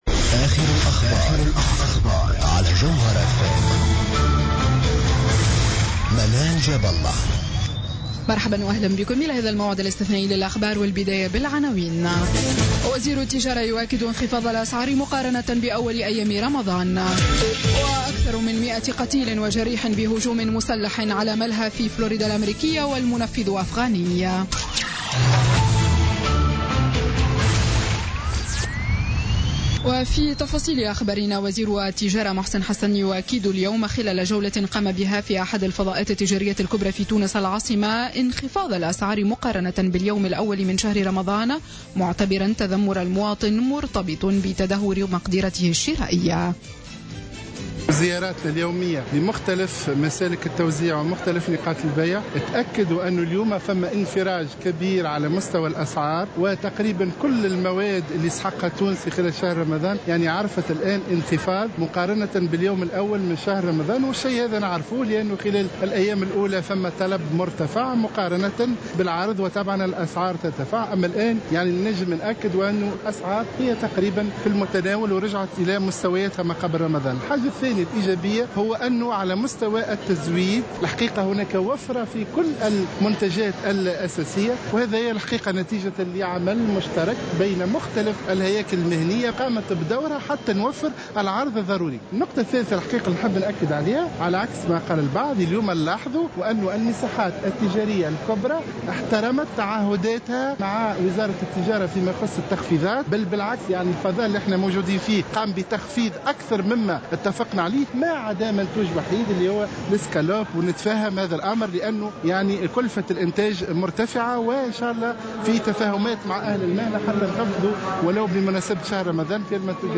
نشرة أخبار الخامسة مساء ليوم الأحد 12 جوان 2016